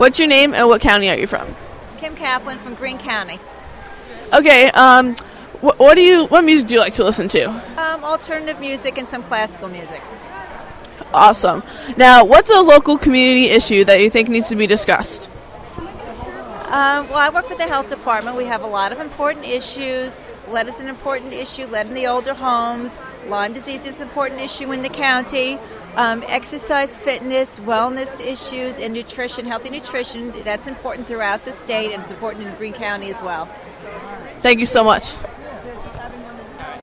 Interview
Greene County Youth Fair: Jul 22, 2010 - Jul 25, 2010